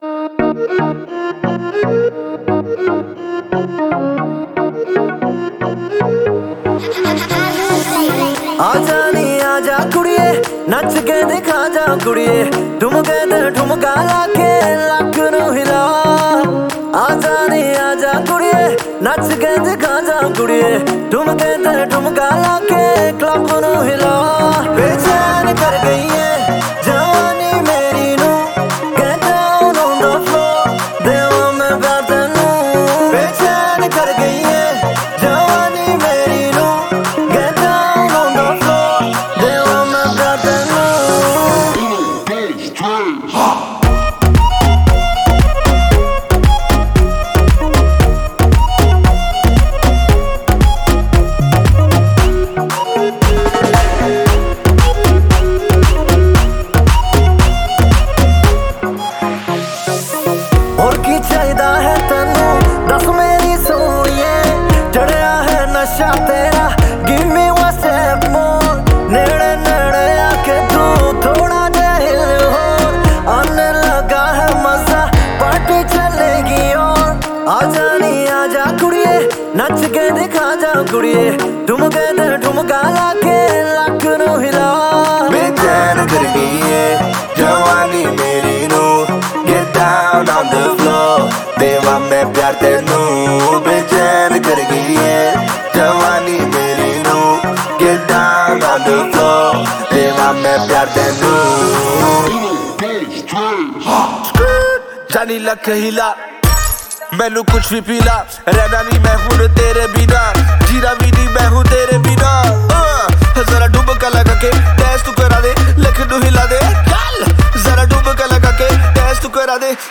Indian POP Mp3 Song